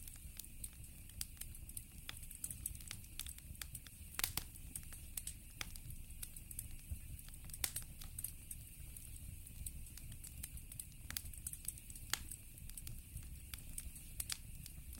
Ambiance Feu de Camp (Broadcast) – Le Studio JeeeP Prod
Bruits d’ambiance autour d’un feu de camp.
Ambiance-Feu-de-camp.mp3